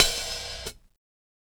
Closed Hats
HIHAT_SPLIT_IN_HALF.wav